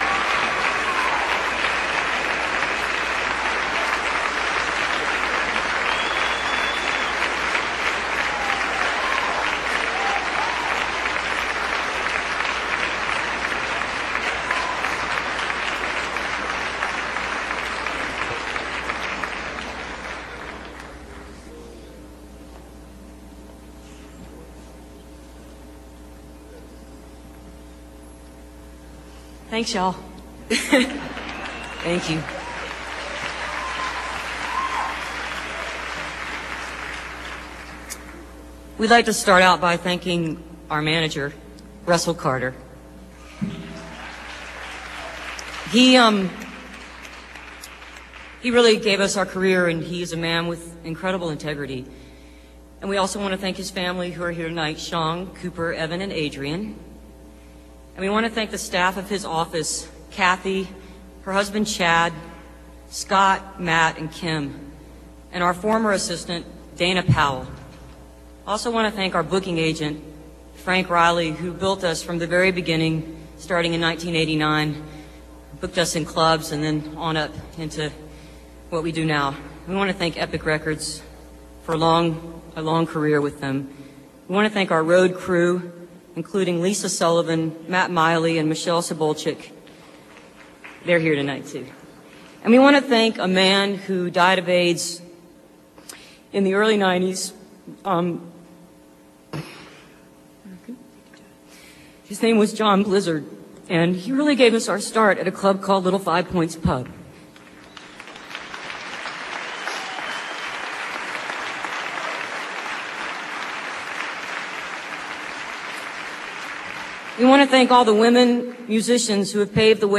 lifeblood: bootlegs: 2003-09-13: thomas b. murphy ballroom of the georgia world congress center - atlanta, georgia (georgia music hall of fame induction)
02. thanks and comments from amy and emily (5:16)